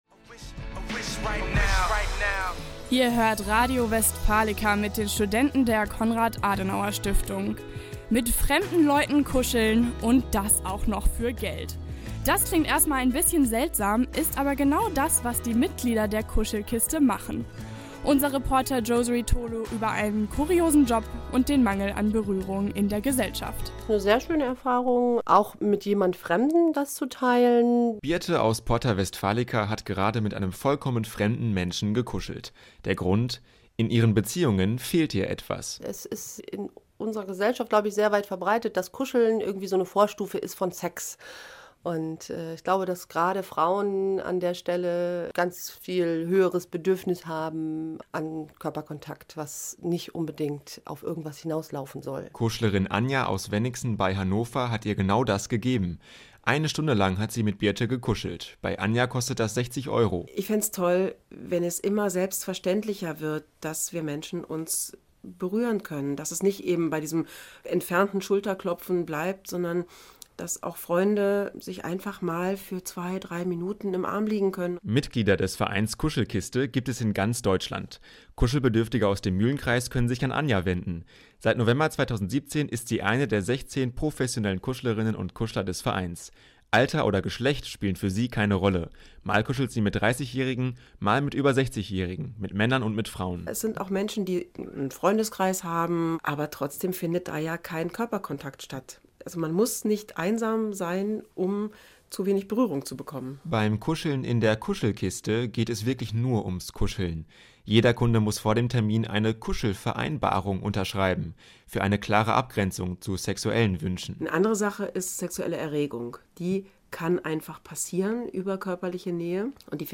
Interview Radio Westfalica